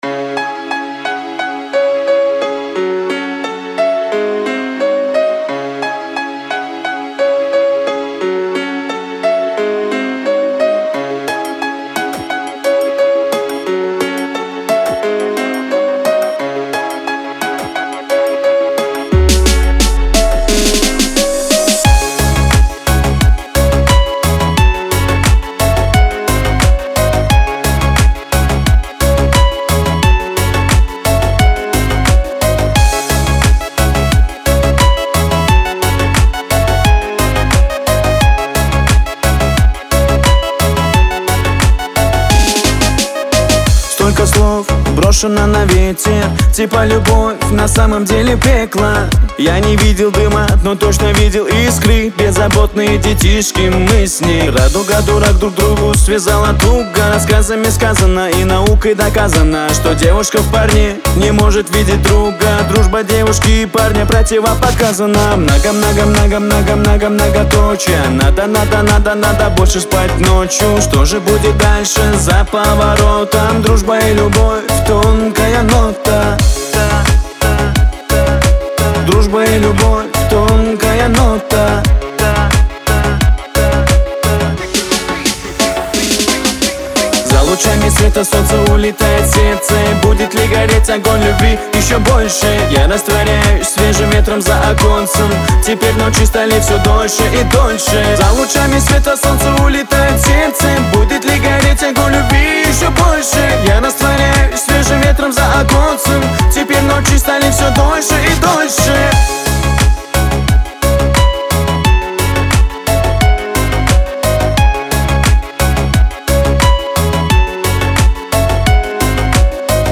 попса